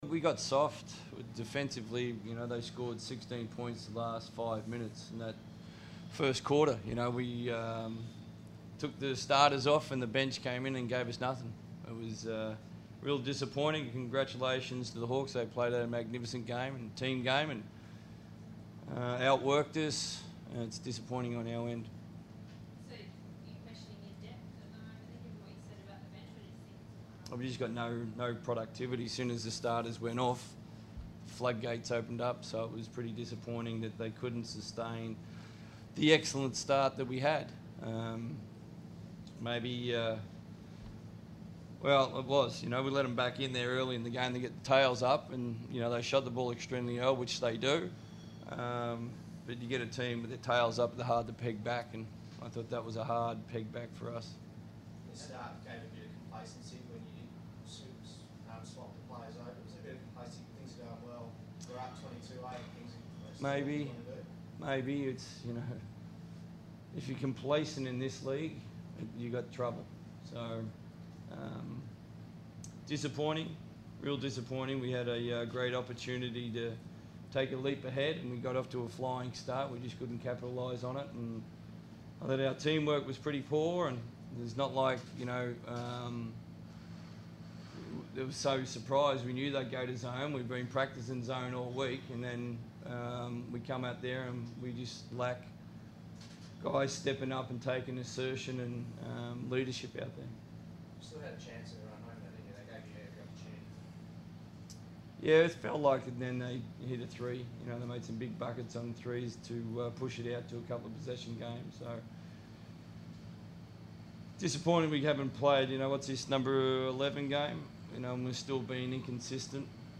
speak to the media following the Perth Wildcats loss versus the Illawarra Hawks.